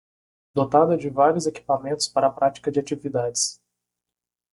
Pronounced as (IPA) /ˈpɾa.t͡ʃi.kɐ/